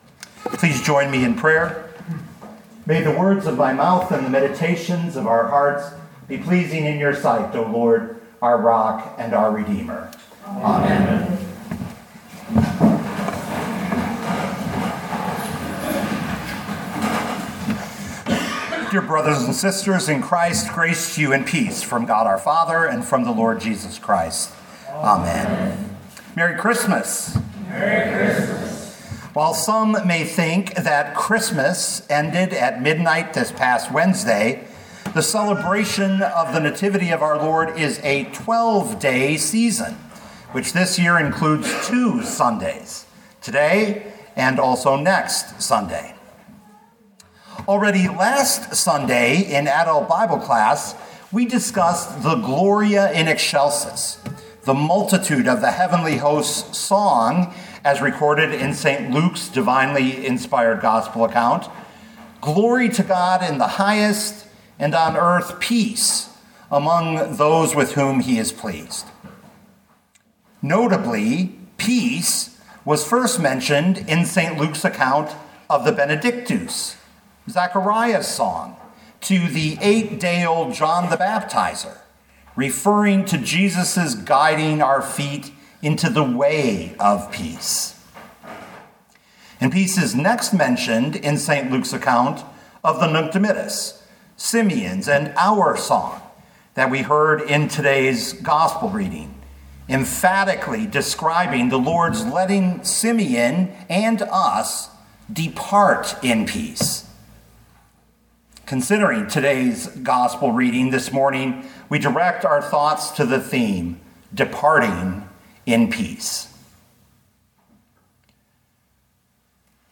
2024 Luke 2:22-40 Listen to the sermon with the player below, or, download the audio.